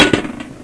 Updated rubble, added rubble and grenade sounds, new pulse-gren function
gBounce1.ogg